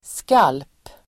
Ladda ner uttalet
skalp substantiv, scalp Uttal: [skal:p] Böjningar: skalpen, skalper Definition: (avskuren) huvudsvål med hår Idiom: vara ute efter någons skalp ("vilja besegra någon") (be out after sby's blood ("wish to overcome sby"))